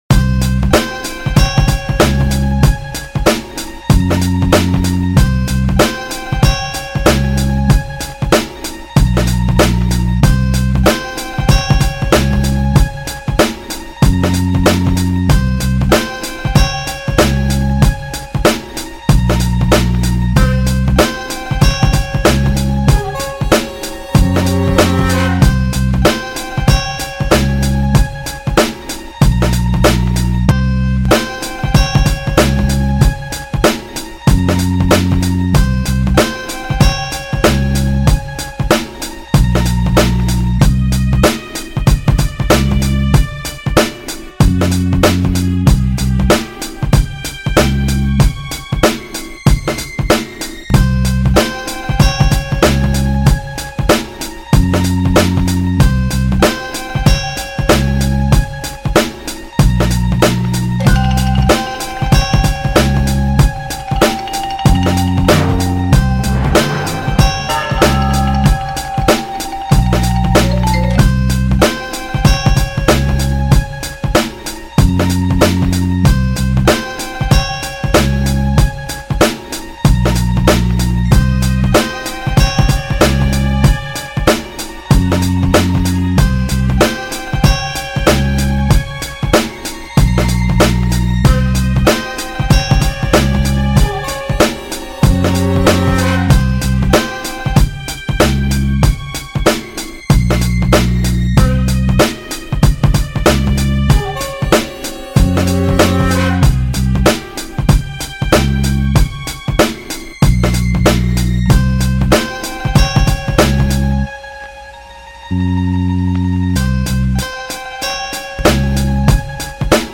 Boom Bap Instrumental